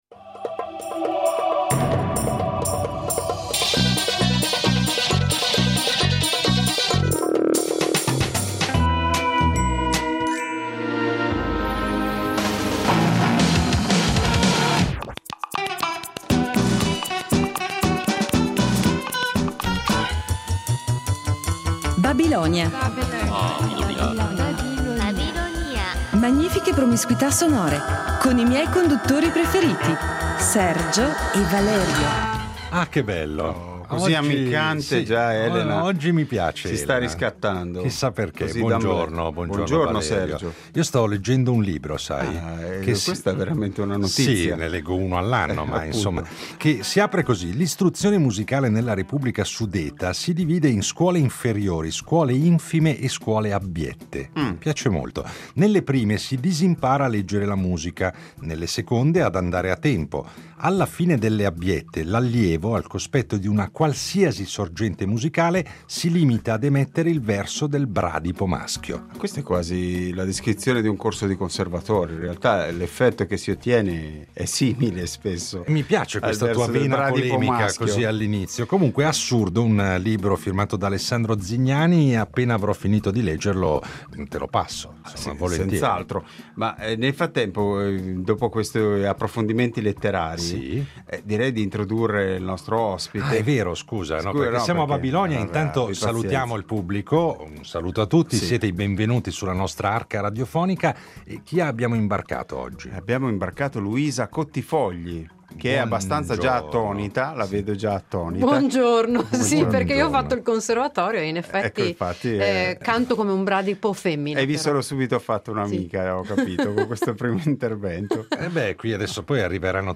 Ecco perché ci sembra doveroso recuperarlo, di tanto in tanto, riunendolo in puntate particolarissime perché svincolate dall’abituale scansione di rubriche e chiacchierate con gli ospiti. Itinerari ancor più sorprendenti, se possibile, che accumulano in modo sfrenato i balzi temporali e di genere che caratterizzano da sempre le scalette di "Babilonia".